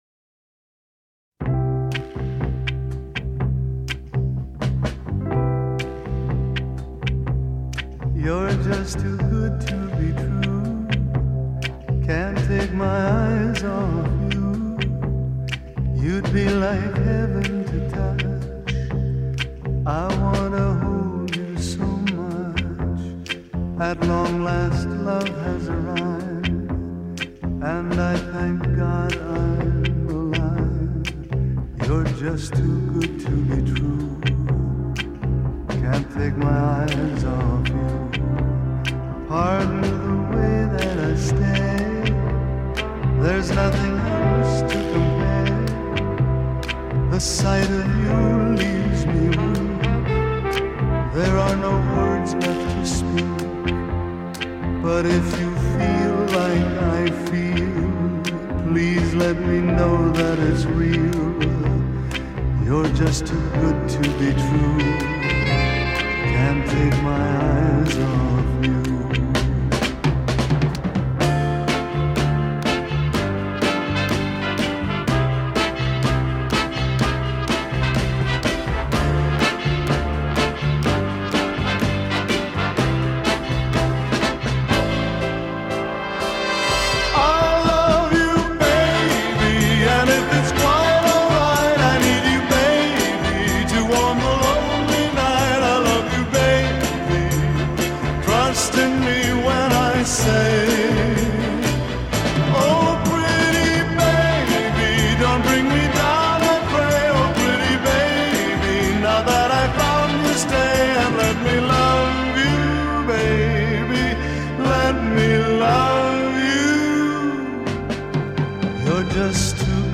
音樂類型：流行音樂